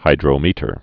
(hīdrō-mētē-ər, -ôr)